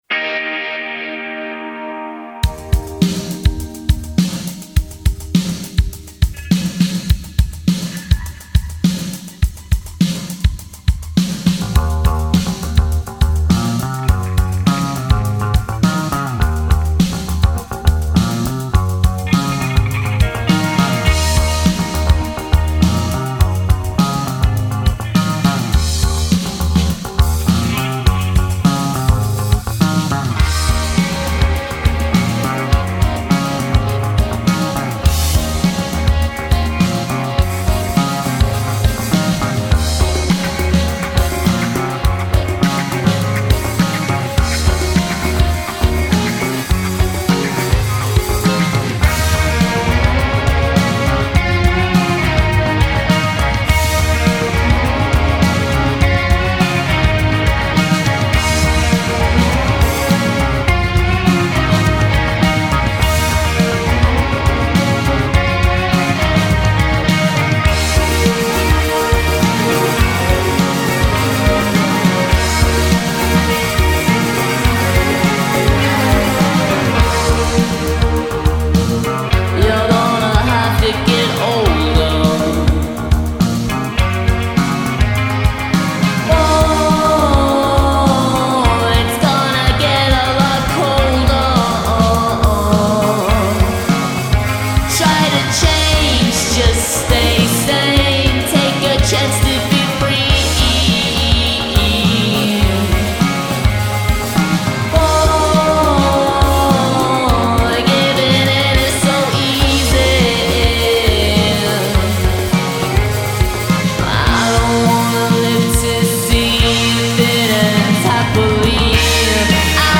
immediate, melodic, atmosphere-heavy songwriting